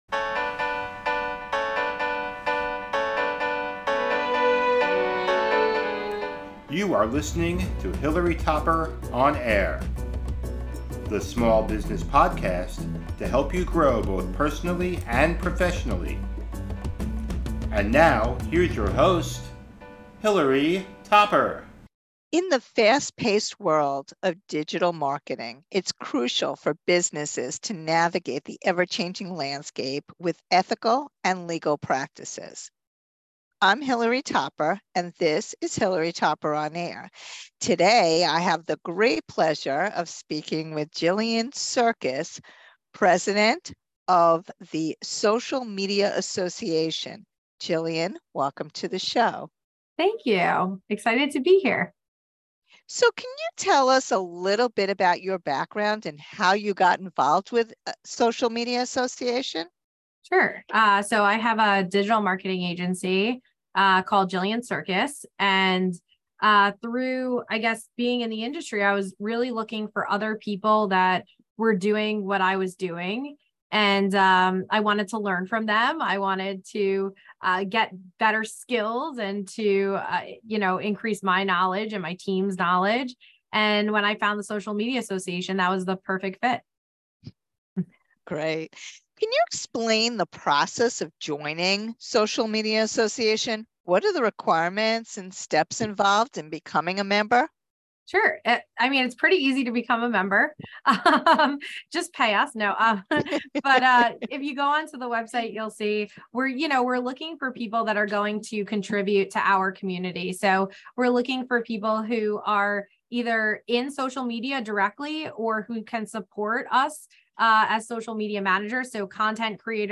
About the Interview In this captivating conversation